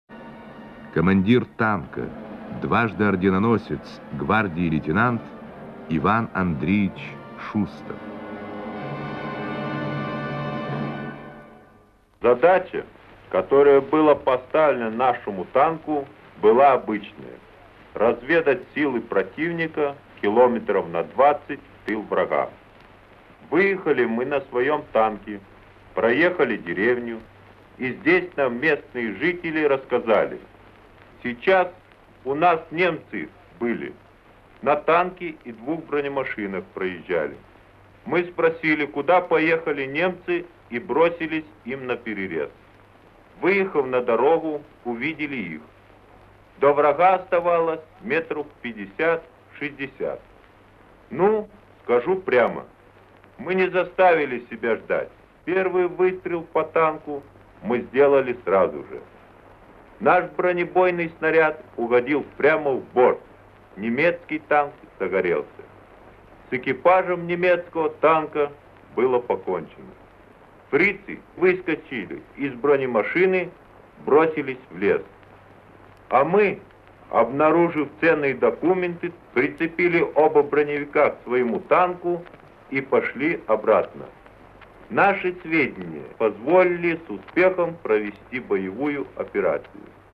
Рассказ танкиста